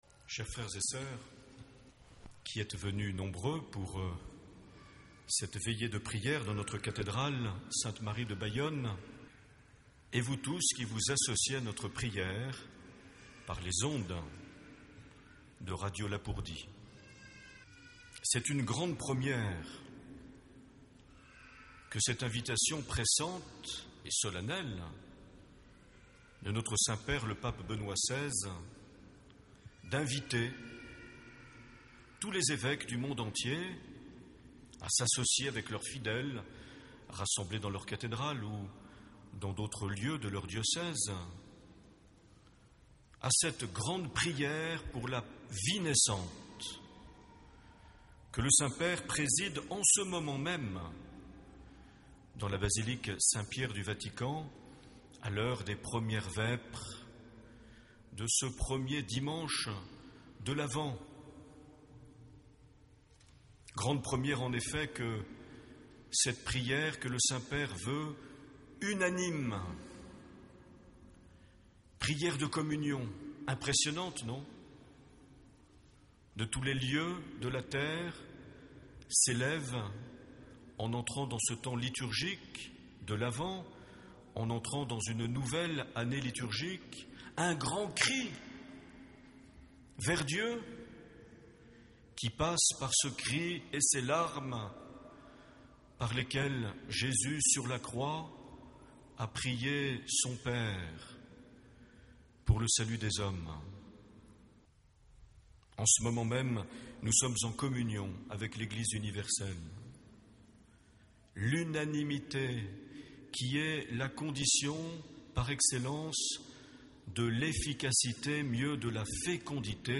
27 novembre 2010 - Bayonne cathédrale - Vêpres Veillée pour la Vie naissante
Accueil \ Emissions \ Vie de l’Eglise \ Evêque \ Les Homélies \ 27 novembre 2010 - Bayonne cathédrale - Vêpres Veillée pour la Vie (...)
Une émission présentée par Monseigneur Marc Aillet